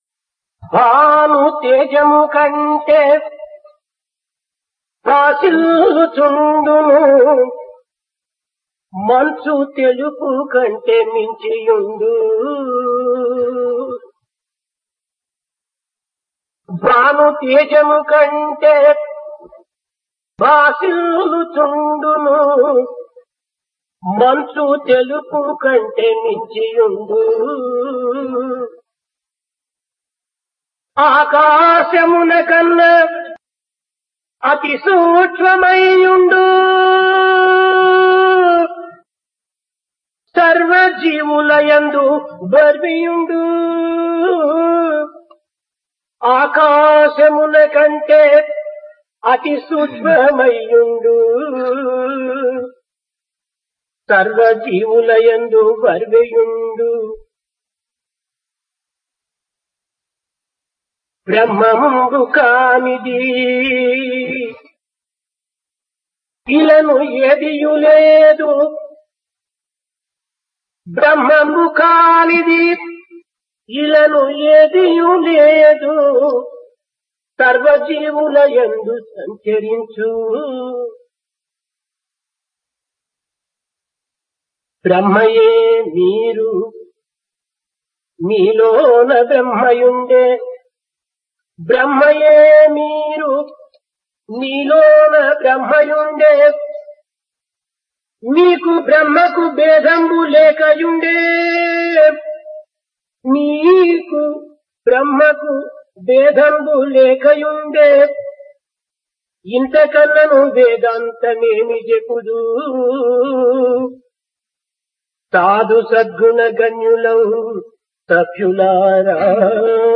Discourse
Place Prasanthi Nilayam Occasion Birthday - 63